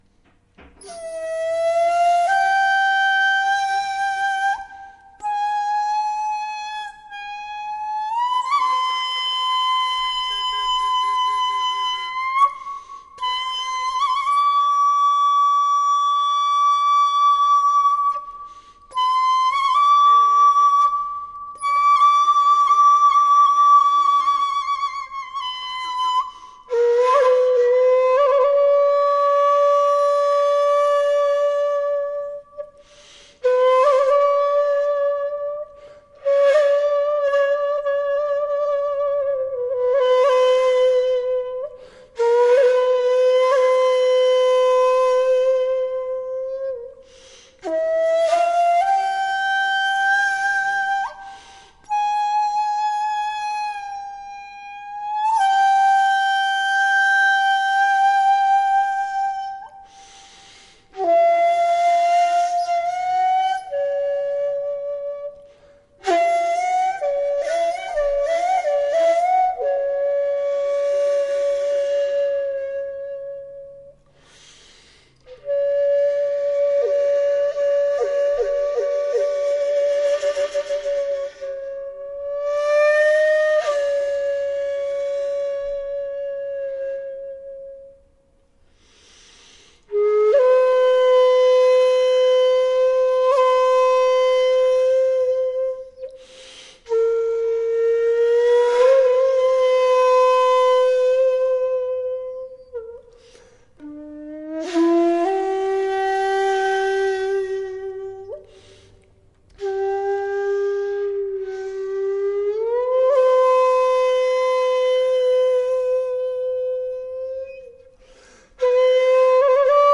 taoism, taoist thought, buddha, yoga, tai chi, shakuhachi
The blowing technique is similar to blowing across the top of a bottle to produce a pleasant resonant tone.
suizen Zen (blowing Zen) on my bamboo gives me a transcendent, if brief, sense of ancestral continuity.